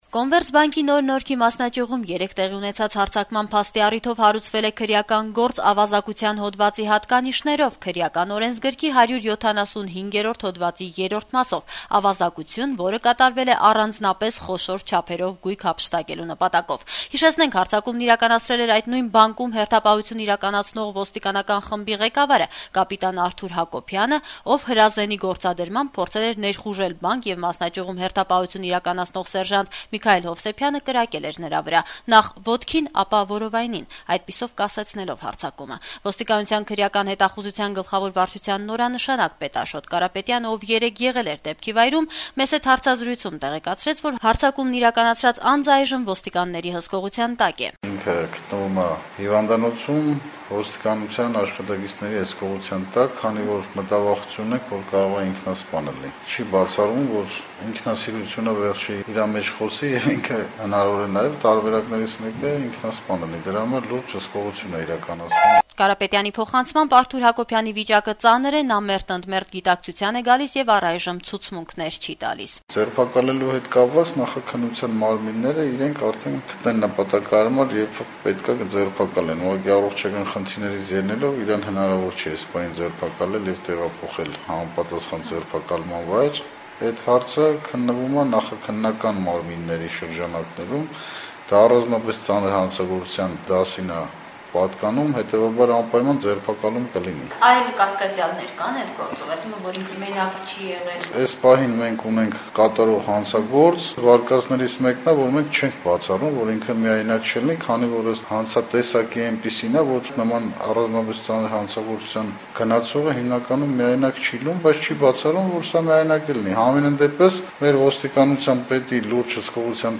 Հարցազրույց քրեական հետախուզության գլխավոր վարչության նորանշանակ պետի հետ